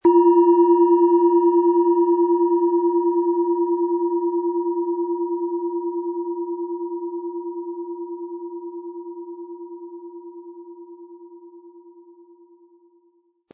Handgearbeitete Planetenton-Klangschale Platonisches Jahr.
Ein unpersönlicher Ton.
Den Ton der hier angebotenen Platonisches Jahr Klangschale finden Sie beim Klangbeispiel.
PlanetentonPlatonisches Jahr